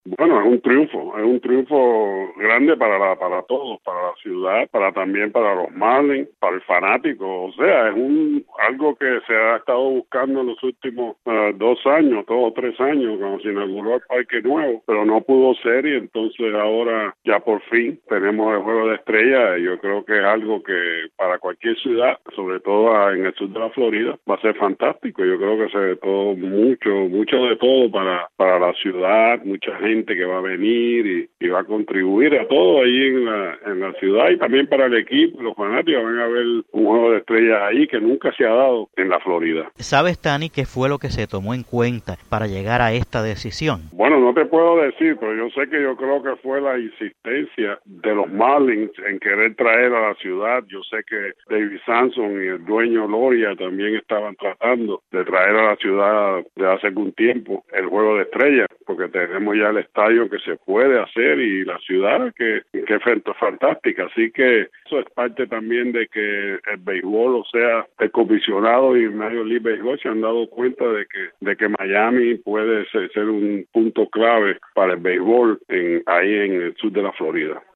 Tany Pérez, miembro del Salón de la Fama desde 2000, entrevistado